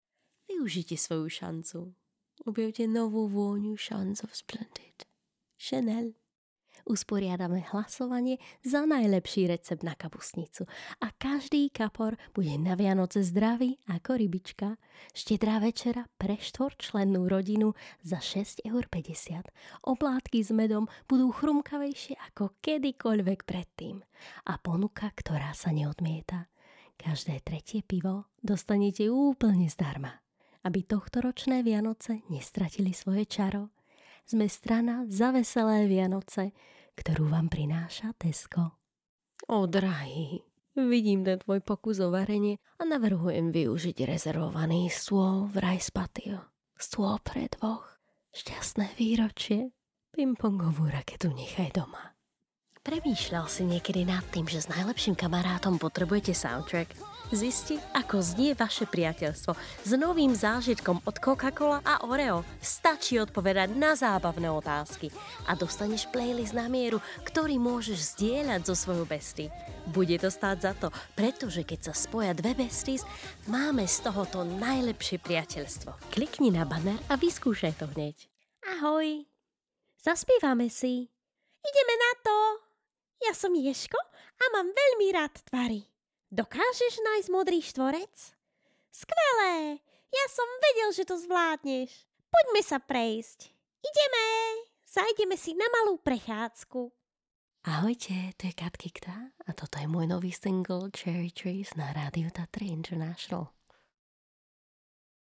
Slovak commercial compilation, shor....mp3